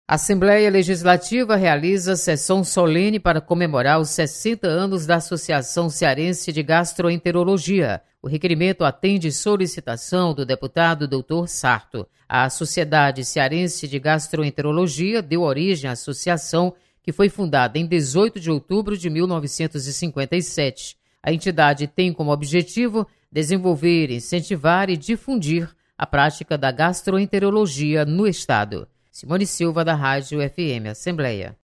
Assembleia Legislativa presta homenagem aos 60 anos da Associação Cearense de Gastroenterologia. Repórter